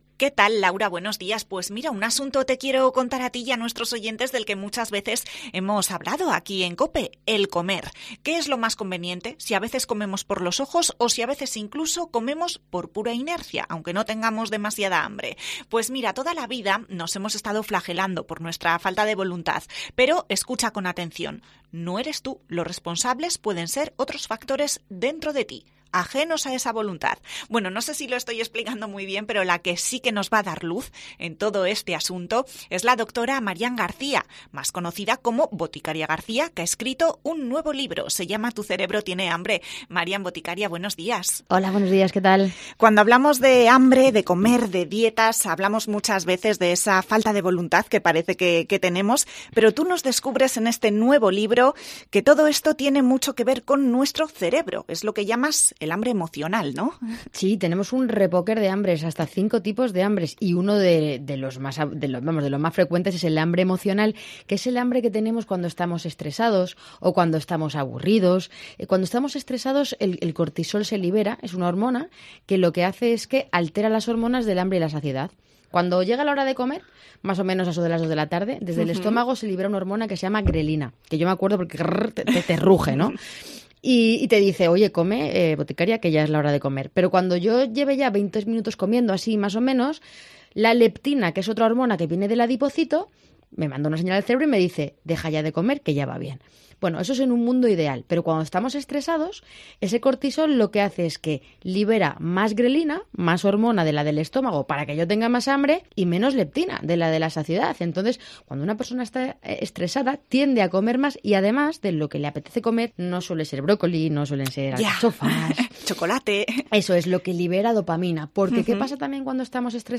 Entrevista a Boticaria García sobre su nuevo libro "Tu cerebro tiene hambre"
Boticaría García ha estado en COPE Zaragoza para hablarnos de su nuevo libro, "Tu cerebro tiene hambre".